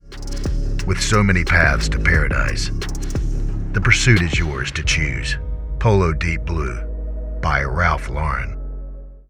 Hire Professional Male Voice Over Talent, Actors & Artists Online
A natural delivery for all project types: Commercials, Narration, Documentaries and Explanation Video.
English (British) English (Australian) Adult (30-50)